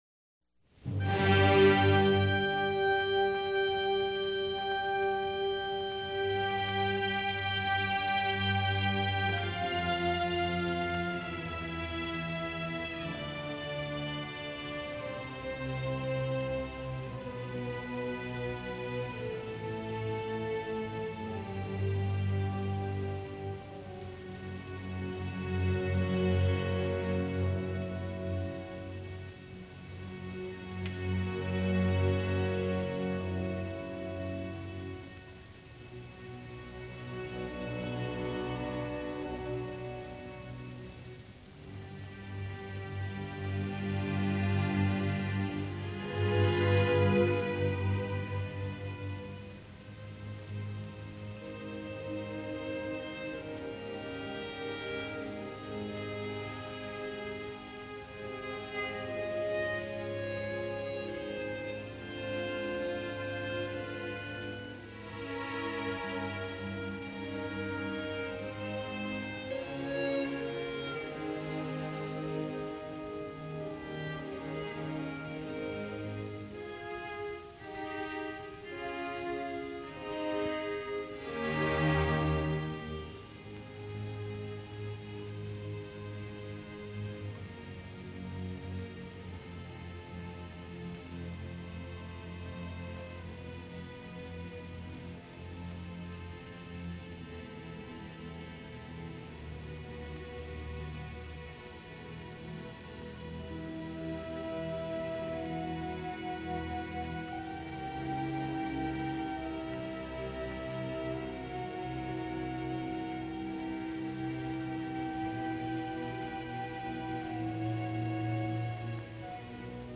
Overture
Concertgebouw Orchestra Amsterdam
Gramophone recording